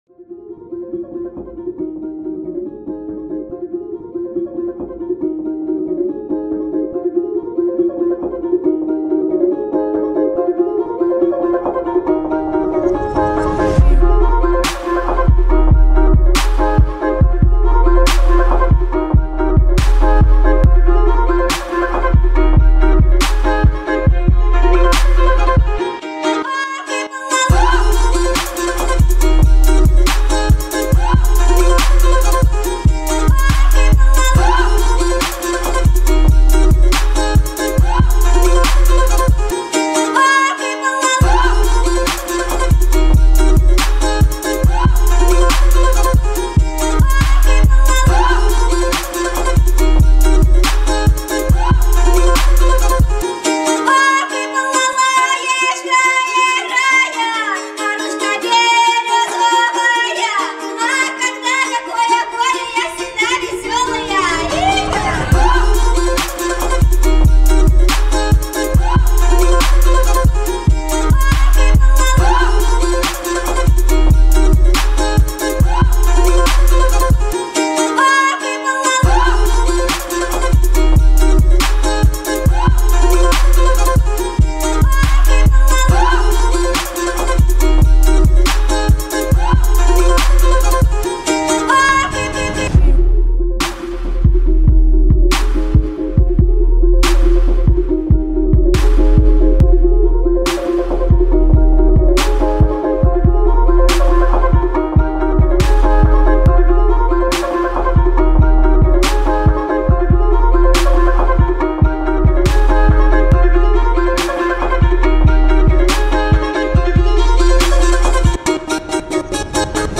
balalaika.mp3